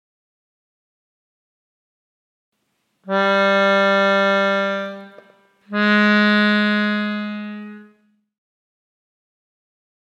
• Sostido (#): sube medio ton o son.
sol_solsostido.mp3